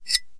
玻璃 陶瓷 " 陶瓷冰淇淋碗金属勺子在碗内发出刺耳的声音 07
描述：用金属勺刮擦陶瓷冰淇淋碗的内部。 用Tascam DR40录制。
Tag: 刮下 金属勺 尖叫 刮去 尖叫 陶瓷 金属